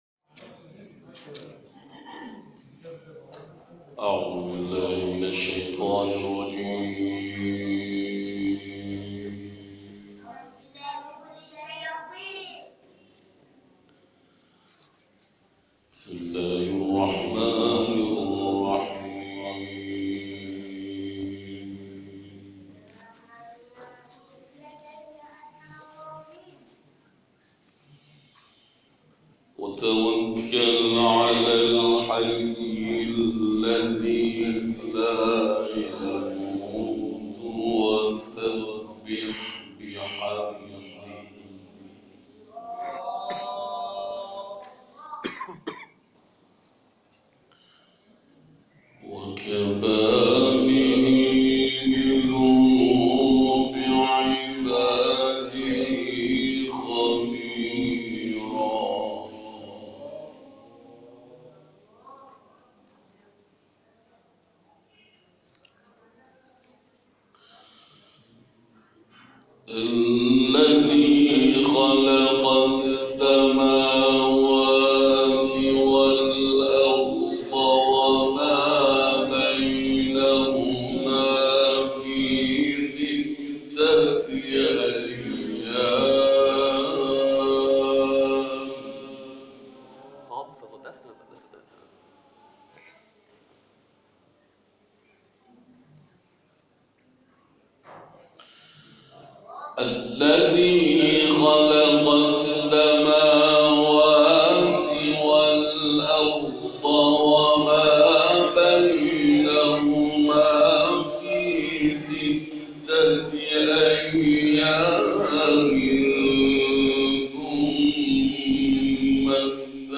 گروه شبکه اجتماعی: قاری کشور در جلسه قرآن مسجد حضرت رقیه(س)، آیاتی از سوره مبارکه فرقان را تلاوت کرد.